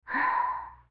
sigh2.wav